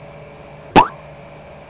Pop Sound Effect
Download a high-quality pop sound effect.
pop-2.mp3